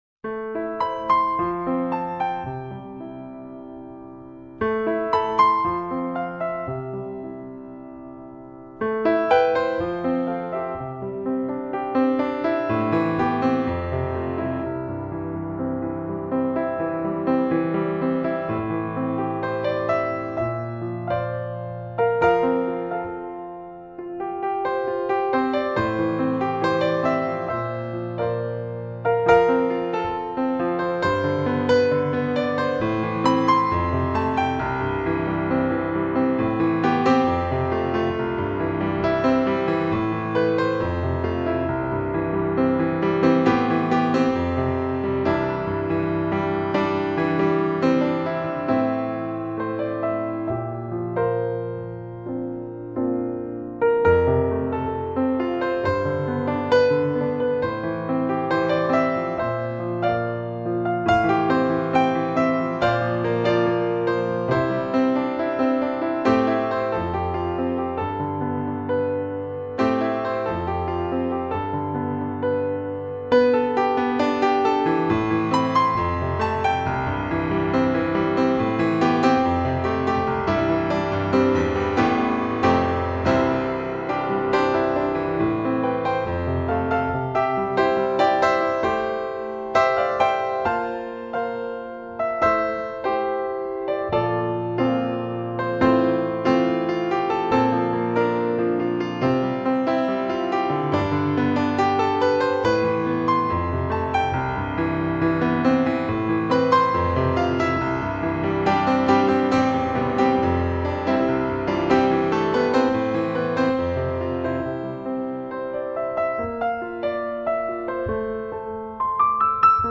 结合了新音乐的优雅乐风，演奏出了让人一听倾心的优美旋律。